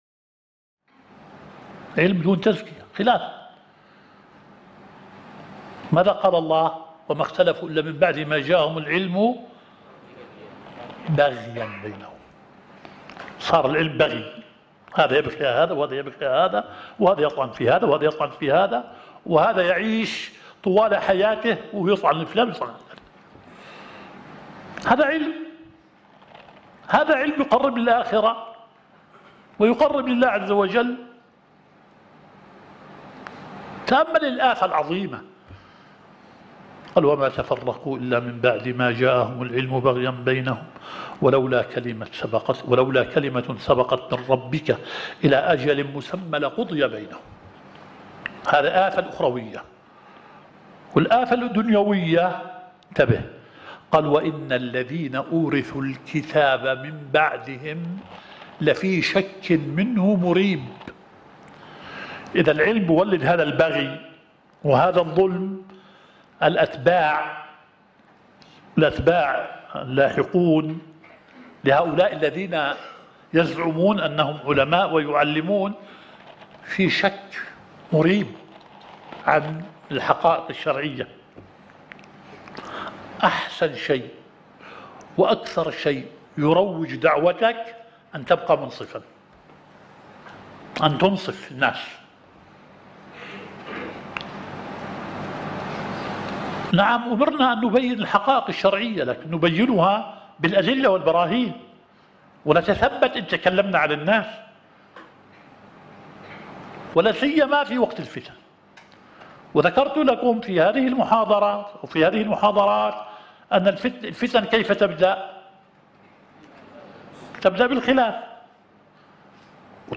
الدورة الشرعية الثالثة للدعاة في اندونيسيا – منهج السلف في التعامل مع الفتن – المحاضرة الثالثة.